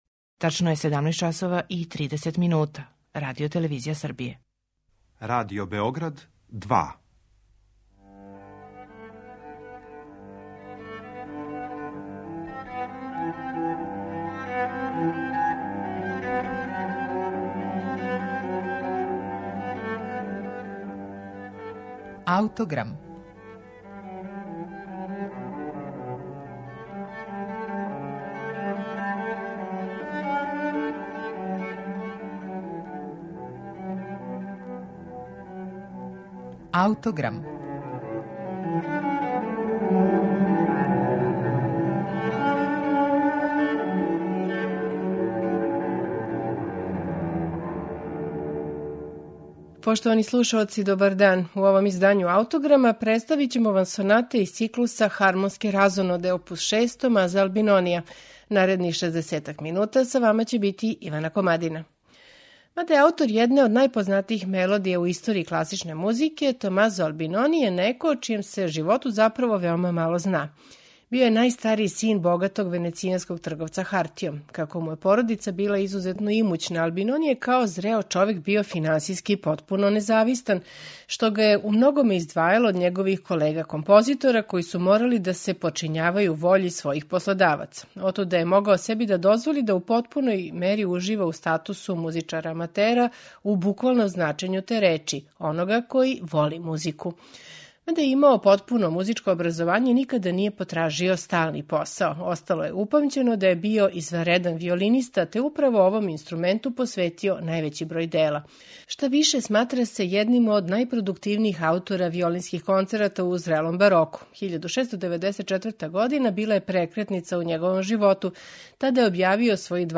Шест соната
на оригиналним инструментима епохе
виолина
виолончело
оргуље